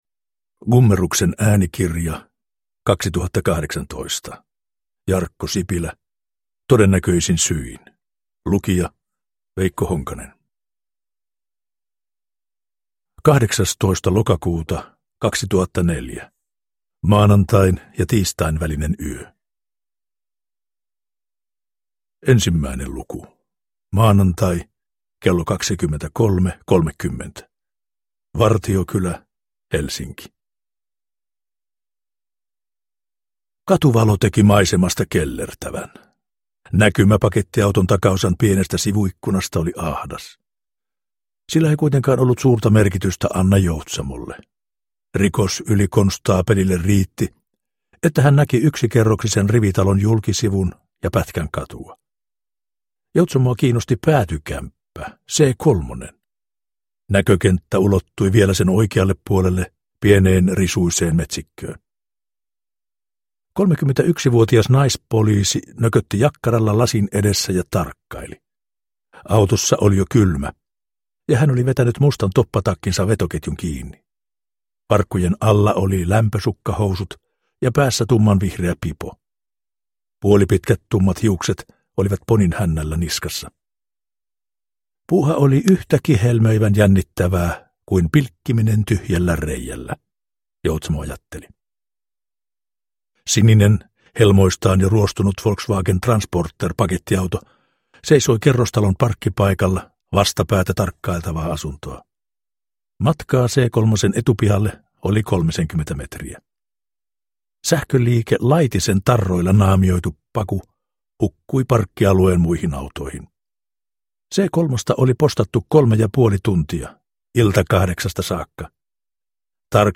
Todennäköisin syin – Ljudbok – Laddas ner